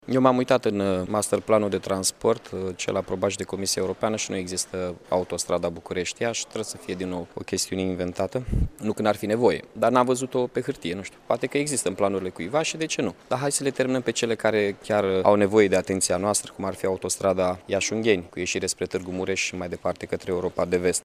Edilul a subliniat importanţa realizării autostrăzii Iaşi –Tîrgu Mureş, afirmând totodată că nu ştie de existenţa vreunui document care să prevadă construirea unei autostrăzi pe ruta Iaşi- Bucureşti: